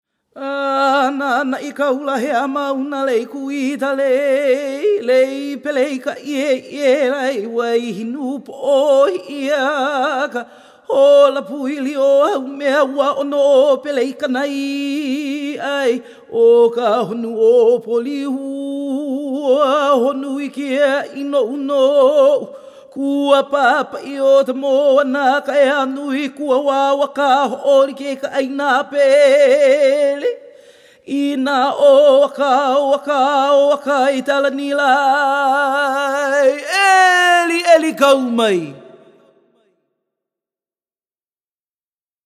Hear a Hawaiian Chant